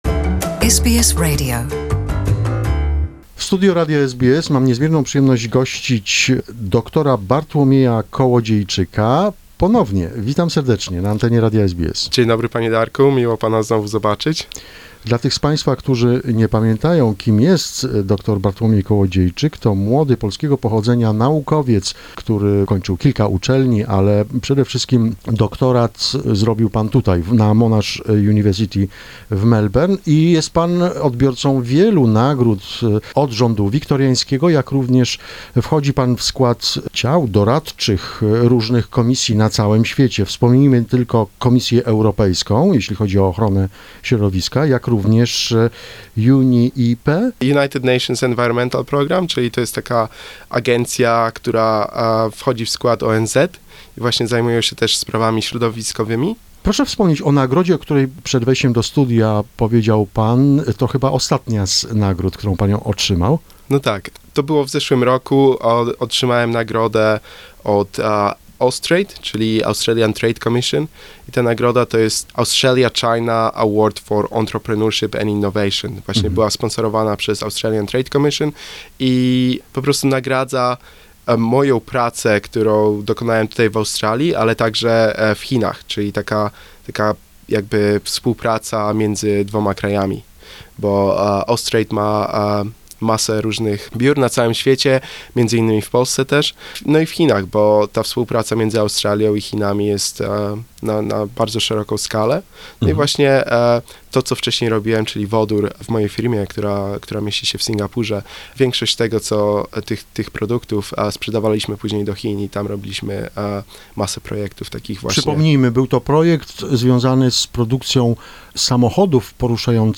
This is part 1 of the interview.